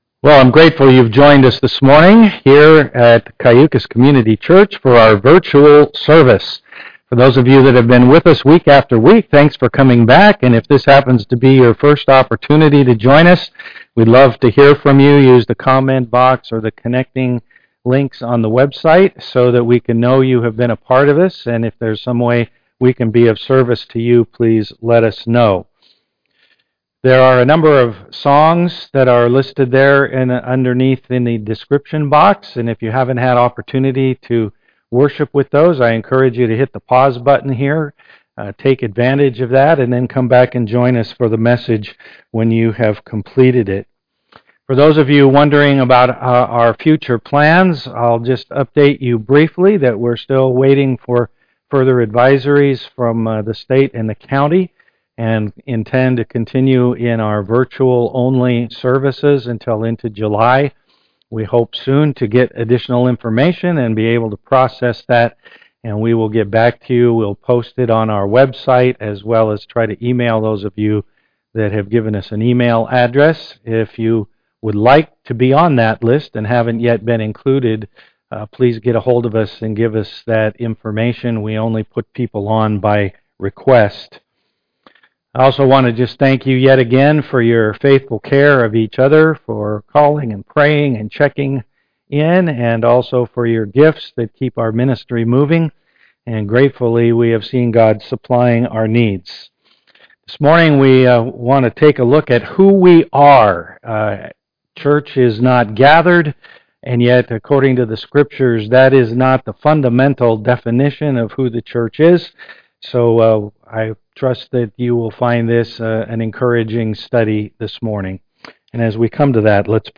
1 Peter 2:9-10 Service Type: am worship Click on the links below to enjoy a time of worship prior to listening to the message.